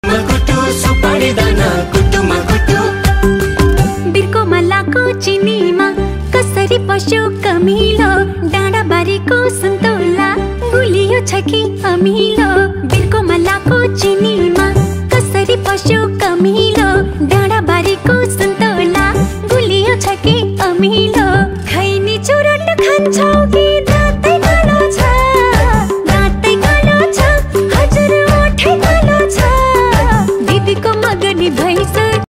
• Quality: High Quality (Clear & Loud)
• Catchy music and punchy beats